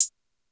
TS Hat 4.wav